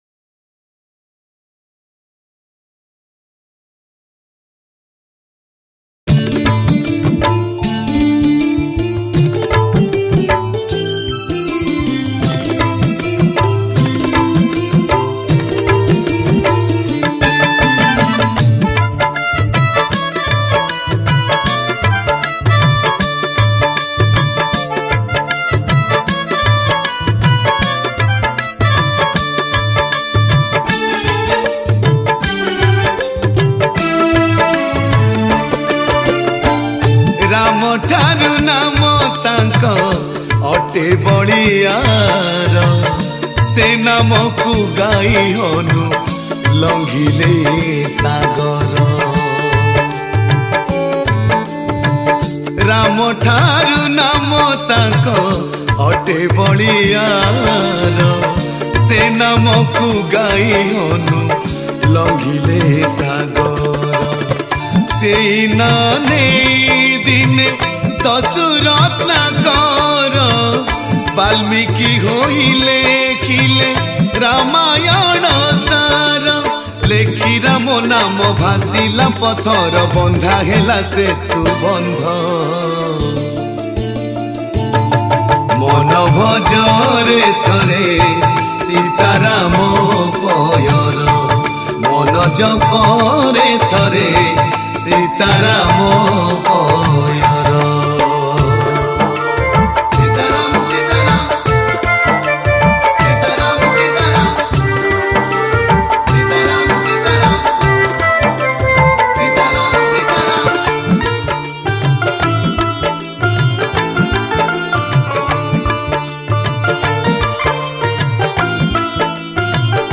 Pana Sankarati Special Bhajan Songs Download
New Odia Ram Bhajan 2022